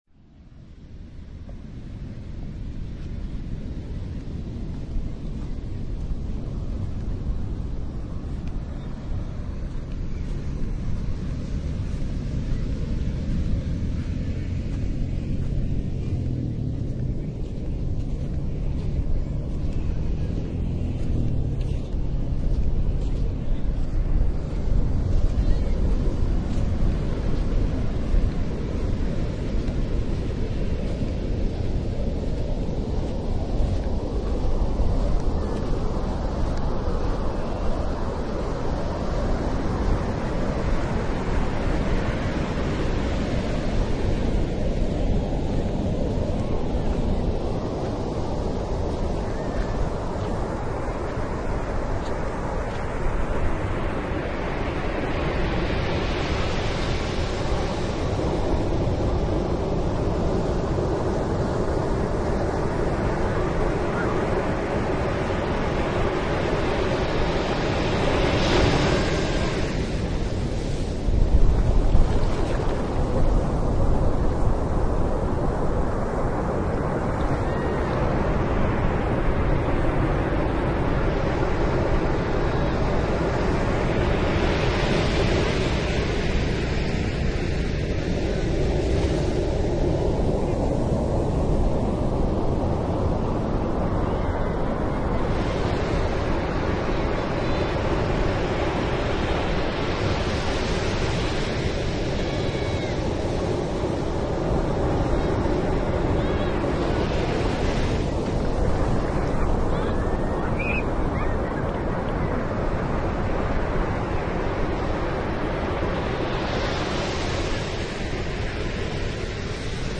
Recorrido por la playa ubicado en Cabeza de Toro municipio de Tonalá en Chiapas.
El recorrido inicia en uno de los restaurantes que se encuentran a la orilla del mar. El recorrido llega hasta dentro del Mar. Existe un sonido constante que decrece al acercarse la ola, además se puede apreciar la espuma debido a las sales del agua.